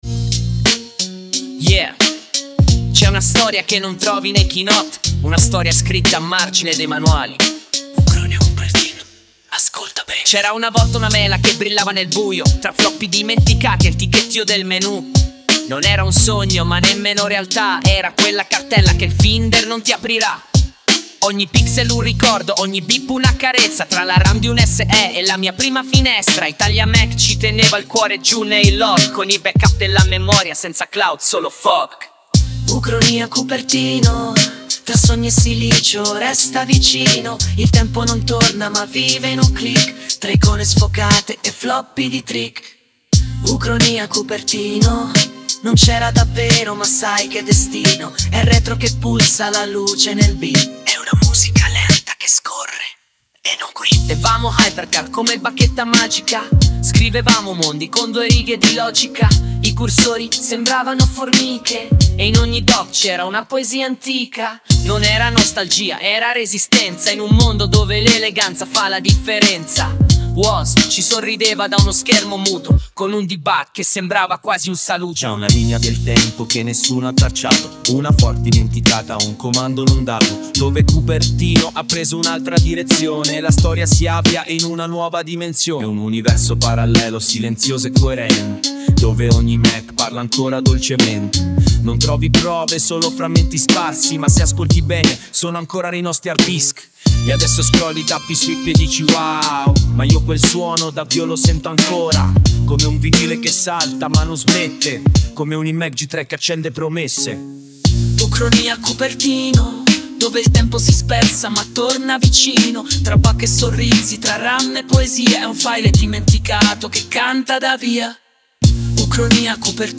Lato B: il brano rap anni 90 ufficiale di Ucronìa: Cupertino
Quando un beat sporco e due voci bastavano per raccontare una realtà parallela.
Un file .AIF inciso su un Mac LC 475 con System 7.5, con un delay sbagliato e un basso che clippa in analogico.
La canzone mescola una voce maschile parlata – tono narrativo, alla Kaos – con un ritornello cantato da una voce femminile piena di malinconia e nostalgia.
I riferimenti sono chiari: boom bapbasso MPCflow narrativo.
Un duetto malinconico e narrativo.